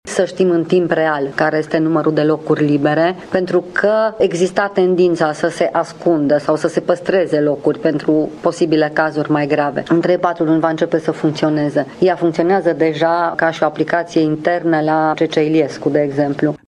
Ministrul Sănătăţii, Sorina Pintea, a declarat într-o conferinţă de presă că este sigură că s-a făcut tot ce s-a putut în acest caz. Sorina Pintea a adăugat că în 3-4 luni ar urma să funcționeze o aplicație prin care să se știe în timp real numărul de paturi disponibile în secțiile de terapie intensivă în spitalele de urgență din București și din centrele universitare: